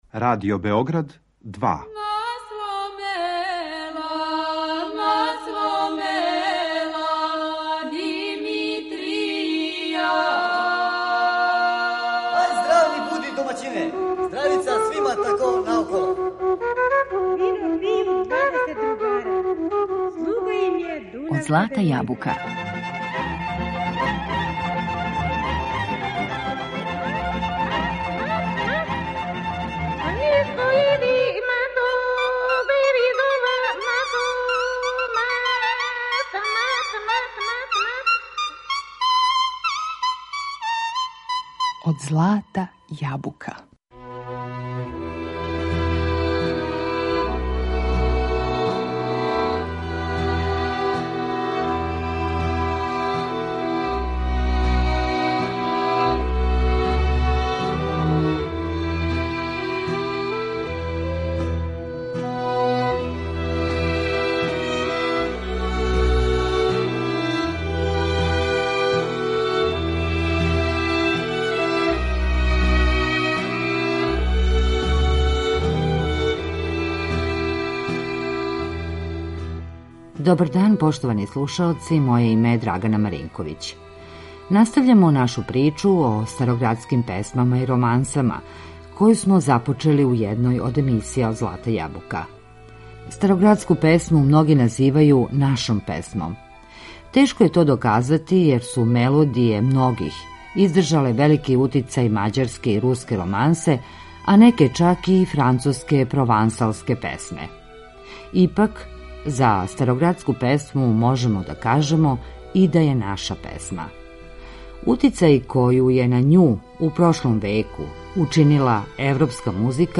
Староградске песме и романсе
Утицај који је на њих у прошлом веку имала европска музика донео је нашим мелодијама салонску ноту.